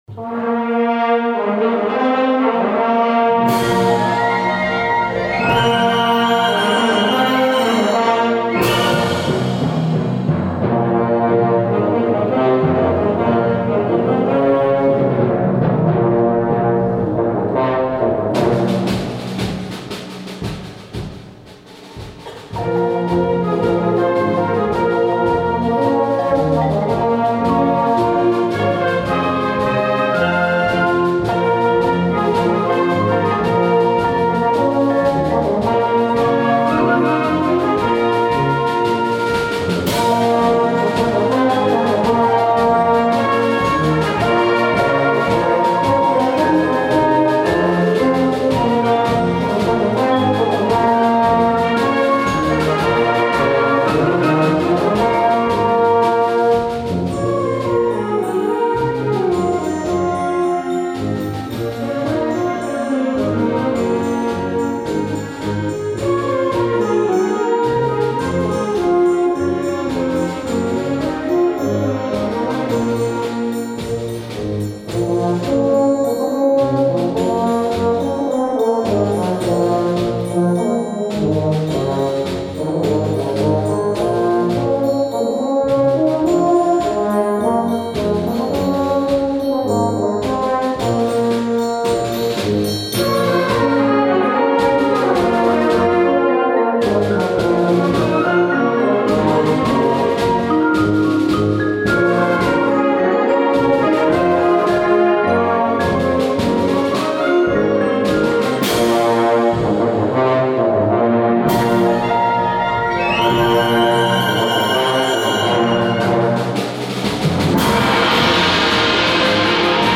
Wind Orchestra Grade 3-5